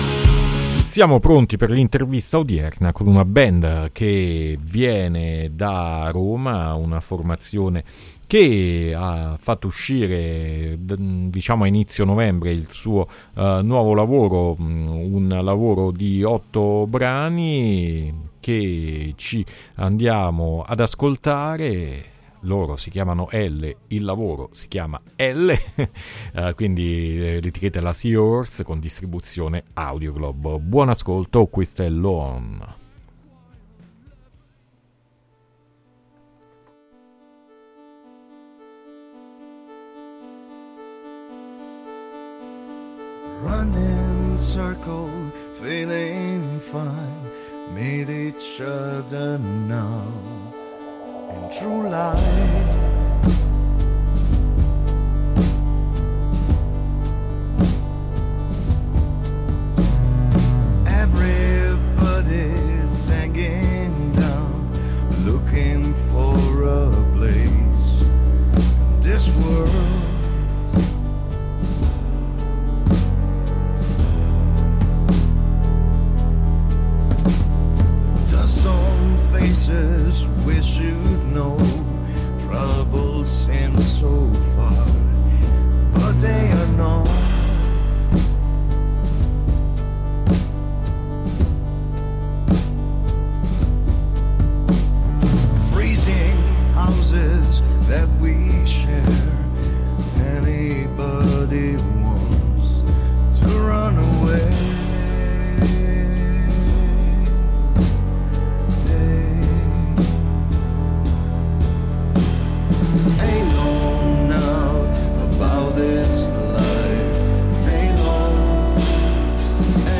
Intervista Elle | Radio Città Aperta